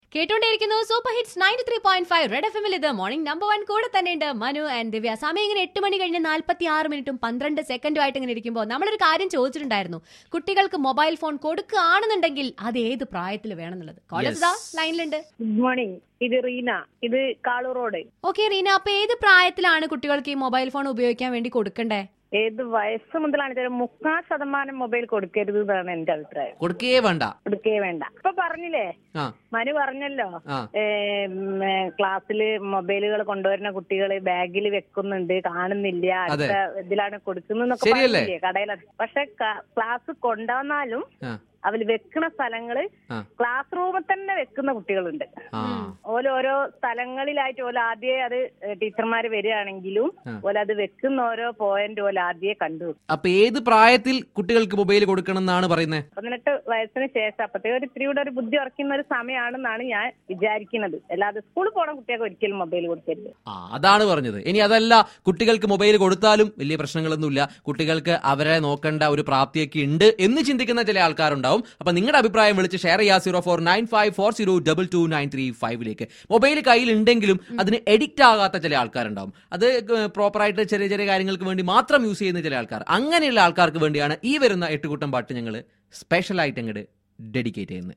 WITH A CALLER REGARDING NEEDS OF MOBILE PHONE S FOR STUDENTS.